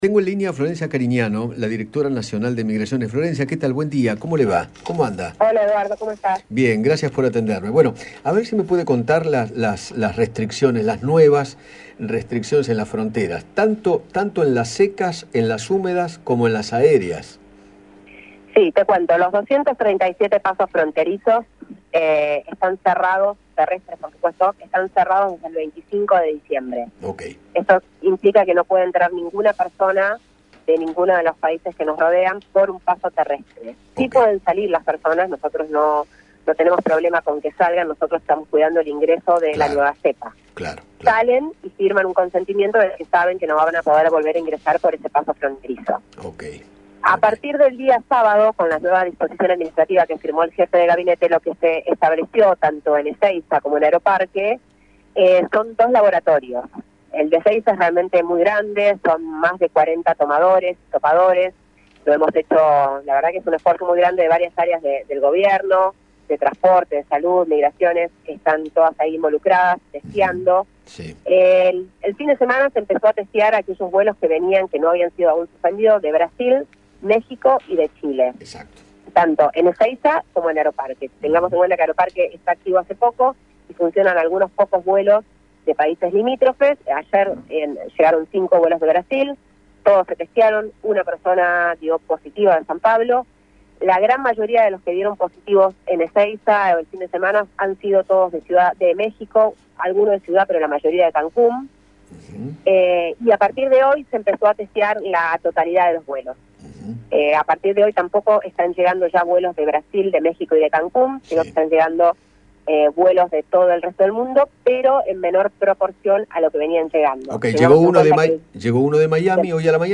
Florencia Carignano, directora nacional de migraciones, dialogó con Eduardo Feinmann sobre el proceso a seguir con los argentinos provenientes del exterior y detalló qué sucede si da positivo de Covid al llegar a Ezeiza.